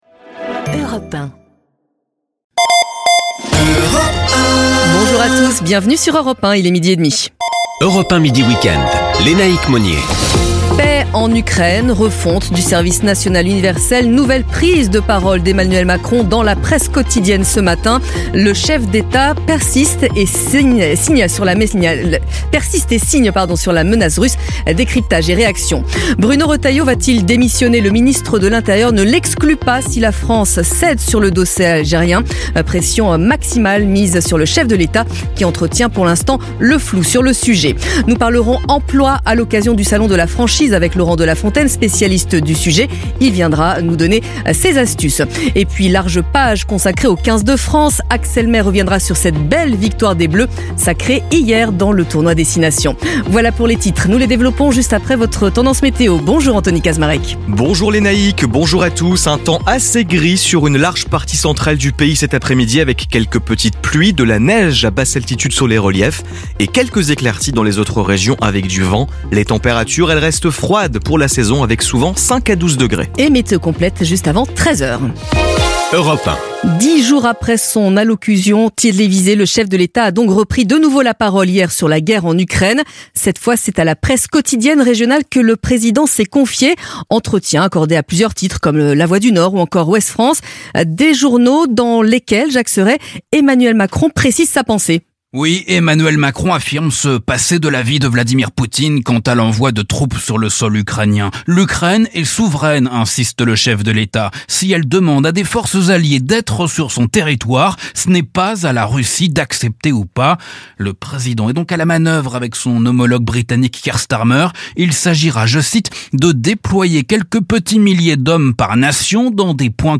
Notre équipe de journalistes expérimentés vous propose des analyses approfondies, des interviews exclusives et des reportages sur l'actualité politique, économique, internationale, culturelle, sportive et sociétale.